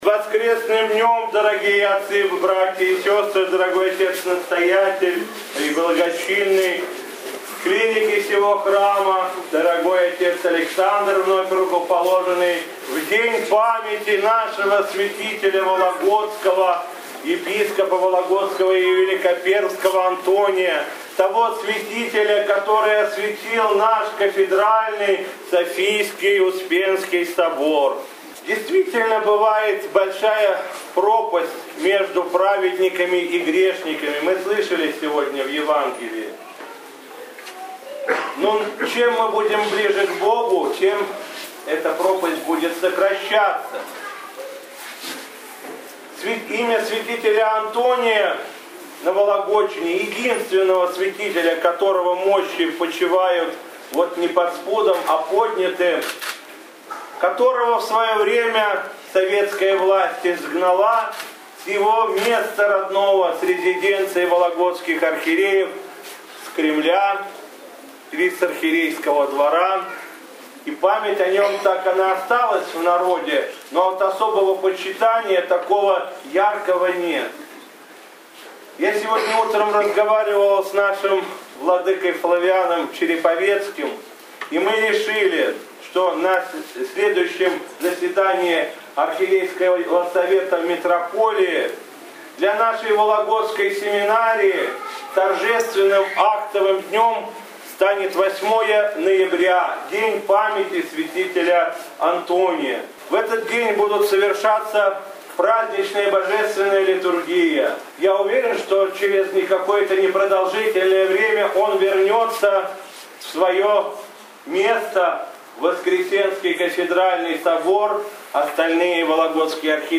В день памяти святителя Антония Вологодского в проповеди по окончании Литургии в храме Николая Чудотворца во Владычной слободе митрополит Игнатий, обращаясь к присутствующим, рассказал, что учитывая многовековой опыт Церкви – опыт христианской любви, выраженной молитвой, связующей Церковь Земную и Небесную, а также считая важным воздать долг молитвенной памяти всем преосвященным, потрудившимся на Вологодчине, а также ректорам, наставникам и выдающимся выпускникам Вологодских духовных школ благословил за каждой Божественной Литургией, на проскомидии совершать поминовение почивших архиереев, ректоров, наставников духовных школ и особо потрудившихся на церковной ниве Вологодской земли.